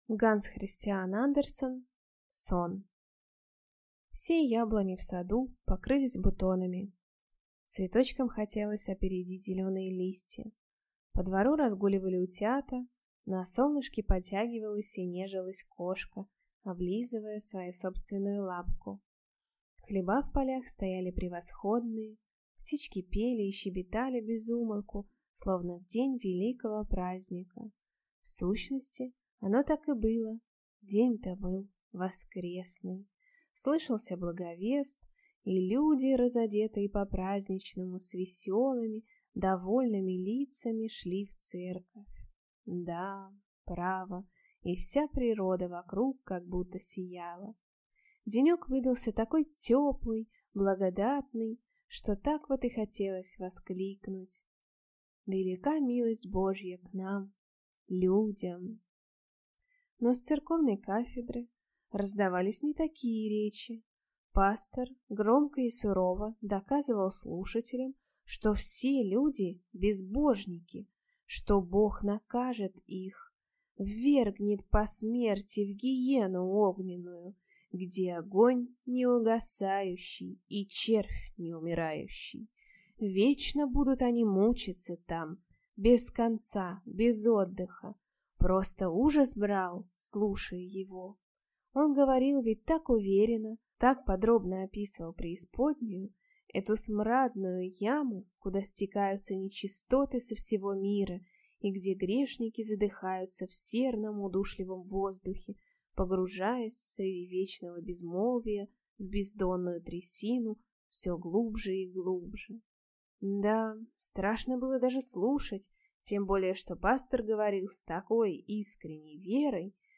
Аудиокнига Сон | Библиотека аудиокниг